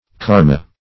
Karma \Kar"ma\, n. [Skr.]